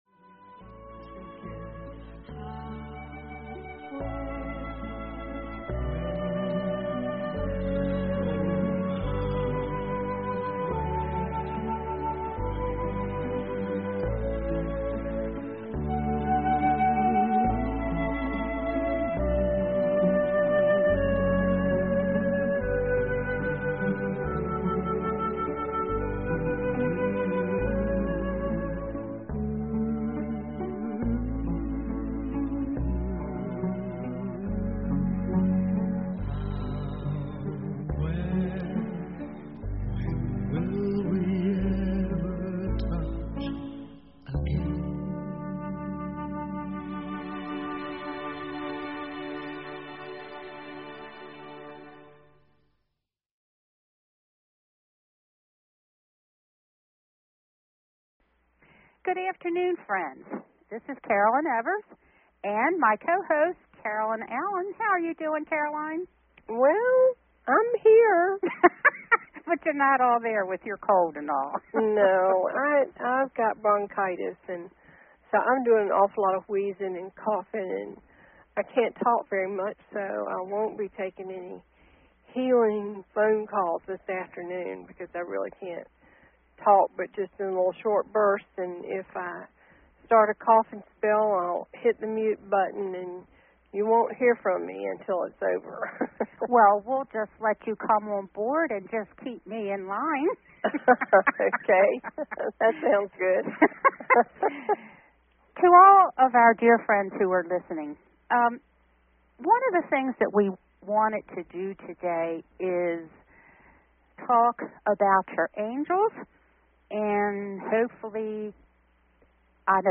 Connecting callers to their angels.